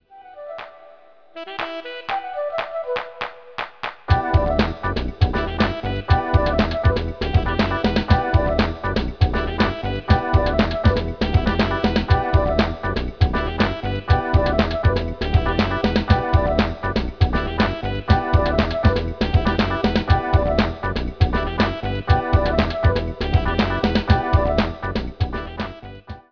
banging breakbeat, funky bass a sound to go mad to.